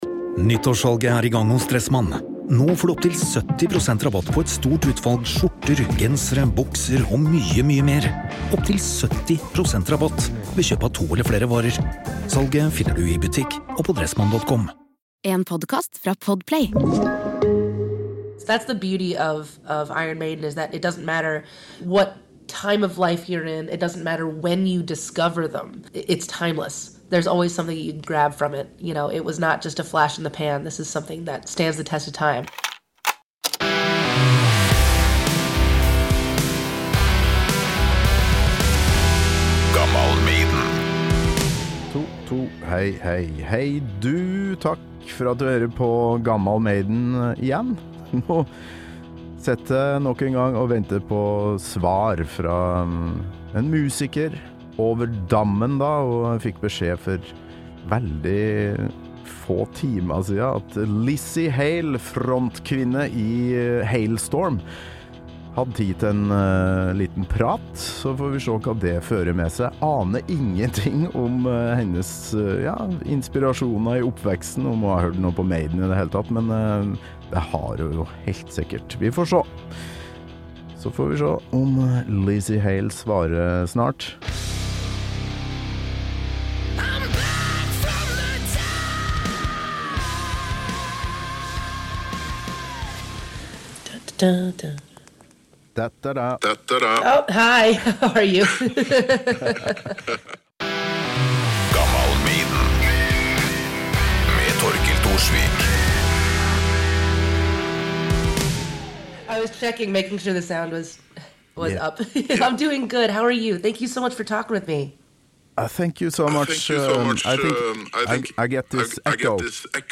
Et par timer senere: Lzzy Hale har kalt meg "honey", vi har kommet oss gjennom noen tekniske problemer - og vi har fått snakka masse om Maiden.